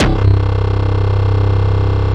Index of /90_sSampleCDs/Roland L-CD701/BS _Synth Bass 1/BS _Wave Bass